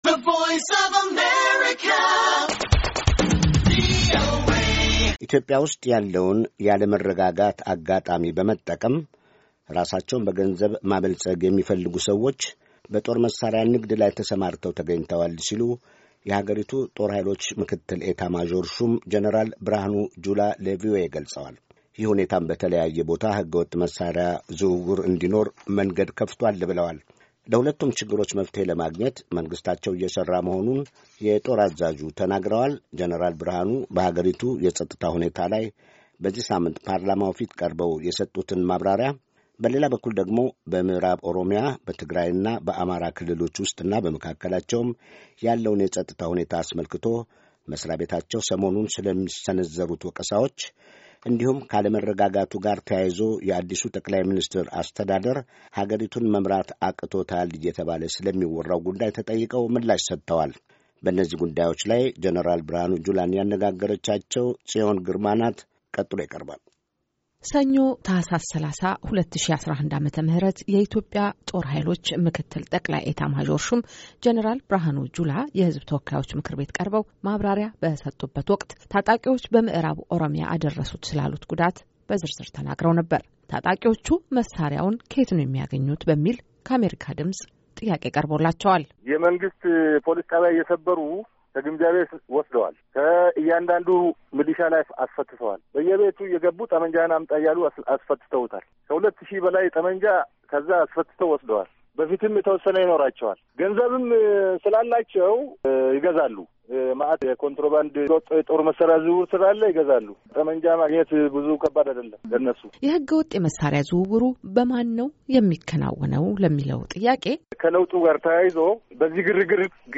የሃገሪቱ ጦር ኃይሎች ምክትል ኤታ ማዦር ሹም ጄነራል ብርሃኑ ጁላ ስለ ሕገ ወጥ የመሳሪያ ዝውውርና ስለ መከላከያ ዩኒፎርም ተጠይቀዋል። ምዕራብ ኦሮሚያ፣ ምዕራብ ጎንደርና ትግራይ መከላከያው የወሰደው እርምጃና ያሳየው ትዕግስት አድሎአዊ ነው ስለመባሉም ተጠይቀዋል።